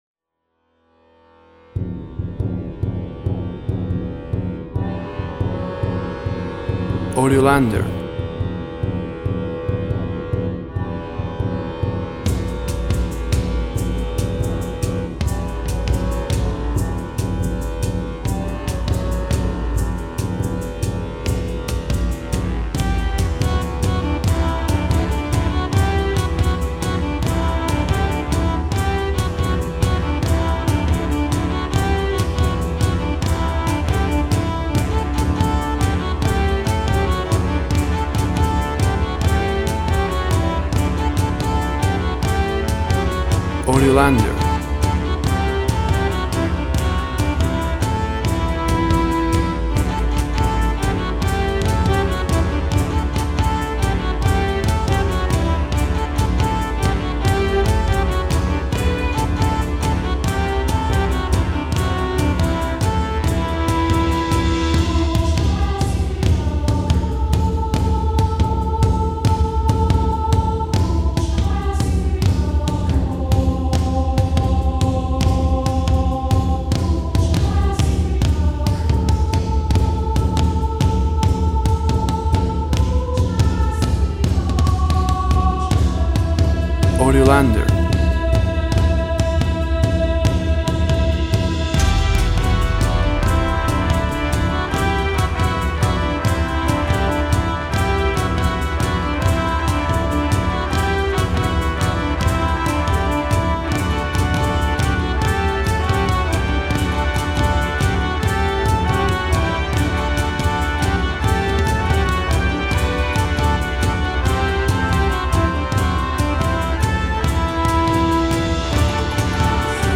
Jolly folk dance tun.
Tempo (BPM) 70